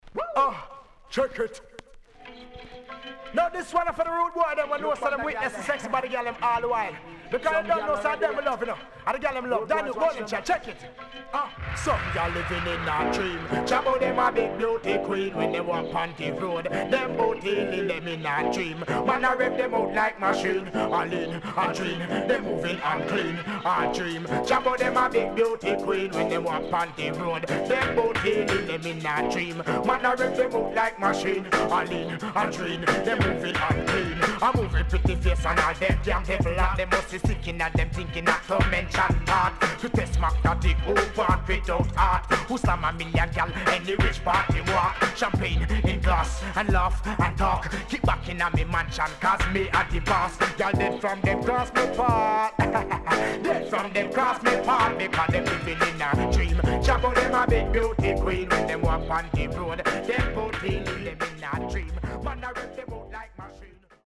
riddim
SIDE B:少しチリノイズ入りますが良好です。